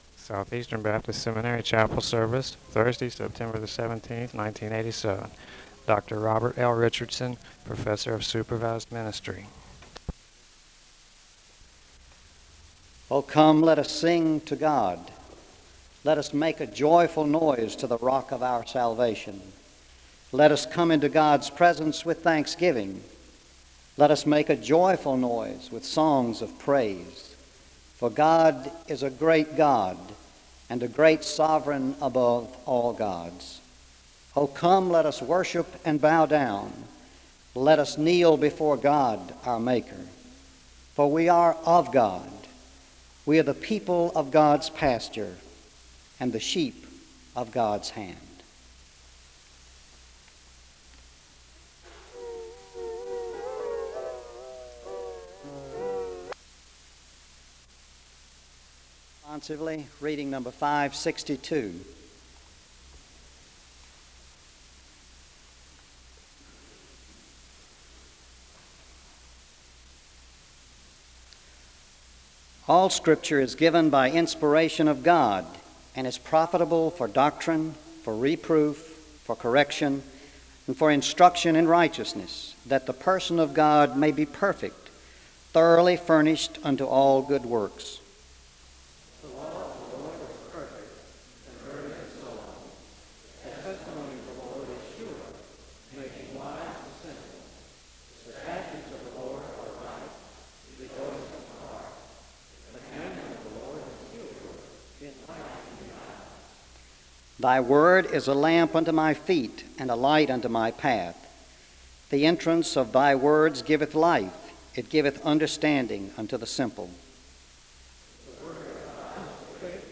The service begins with a call to worship from the Psalms (0:00-1:09). There is a responsive reading (1:10-2:20). There is a moment of prayer (2:21-4:35).
The service closes with a blessing (21:02-21:15).